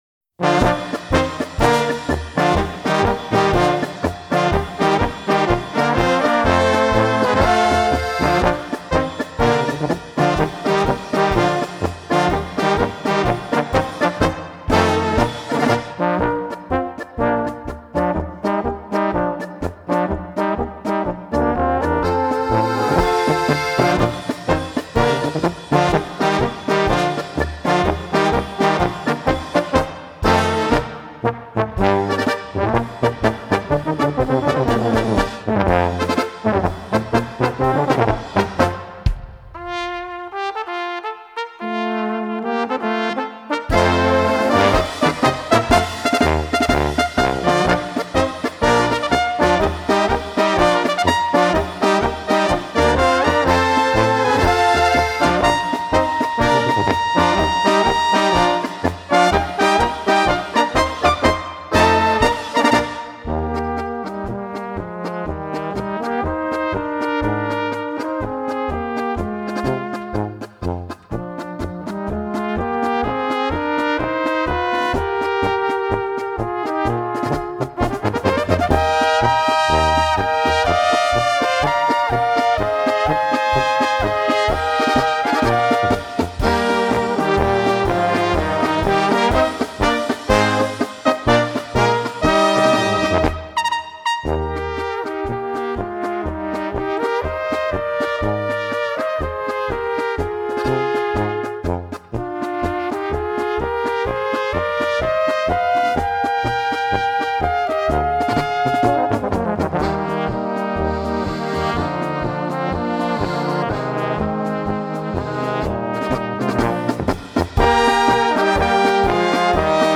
Gattung: Polka für kleine Besetzung
Trompete
1.Flügelhorn B
Tenorhorn B
Bariton B/C
Tuba B/C
Schlagzeug